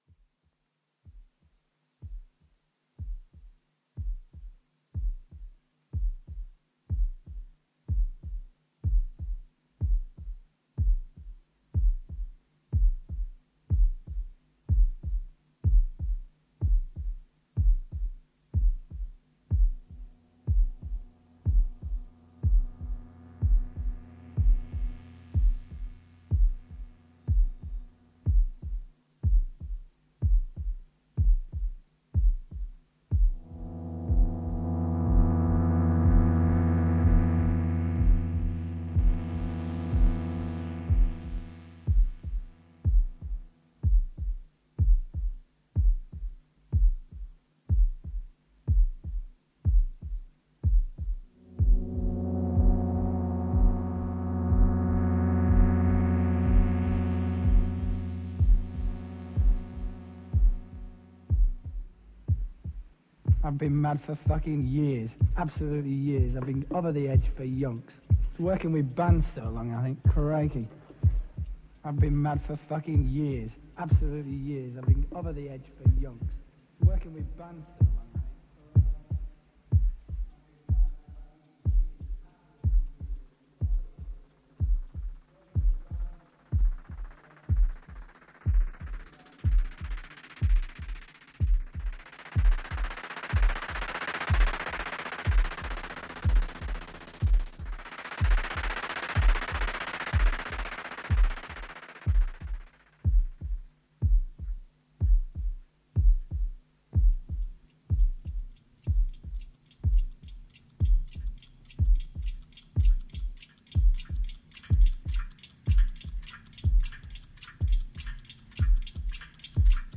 recorded live
In Concert At Wembley Pool, London